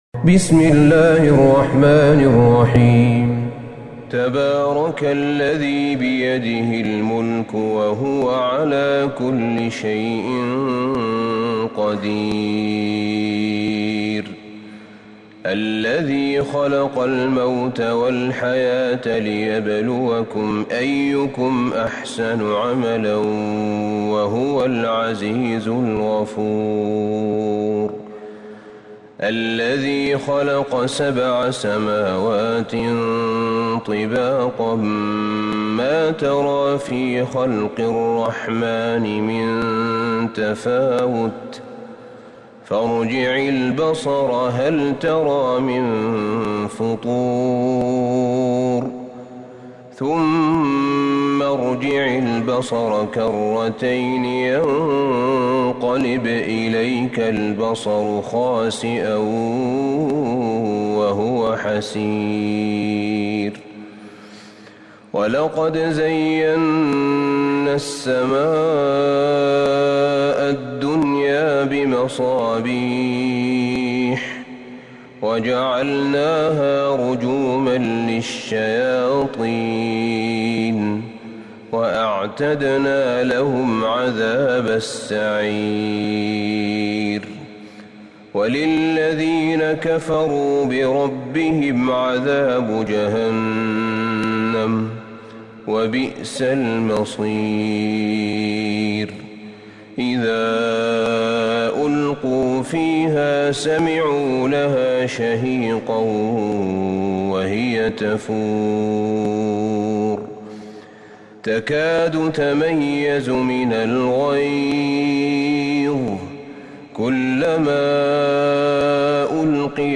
سورة الملك Surat Al-Mulk > مصحف الشيخ أحمد بن طالب بن حميد من الحرم النبوي > المصحف - تلاوات الحرمين